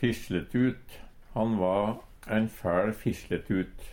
DIALEKTORD PÅ NORMERT NORSK fisjletut sladrehank Eintal ubunde Eintal bunde Fleirtal ubunde Fleirtal bunde Eksempel på bruk Han va ein fæL fisletut Hør på dette ordet Ordklasse: Substantiv hankjønn Attende til søk